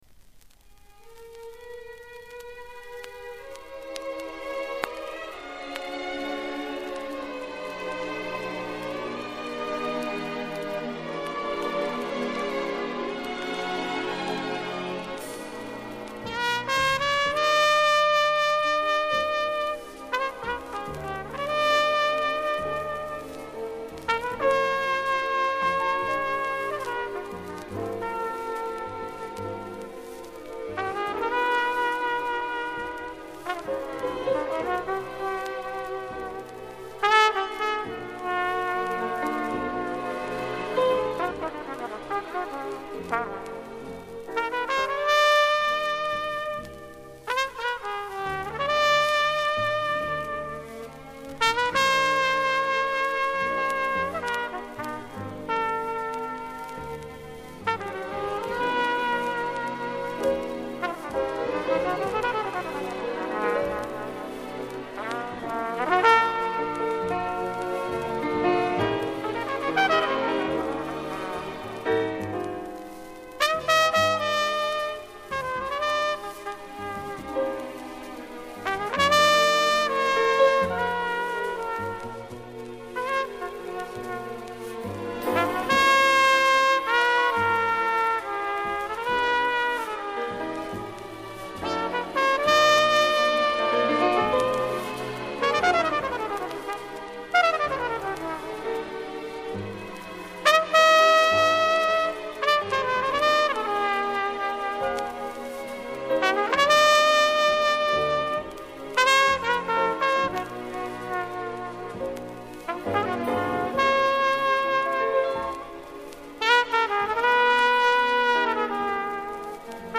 Еше один джазовый музыкант-трубач из Чехословакии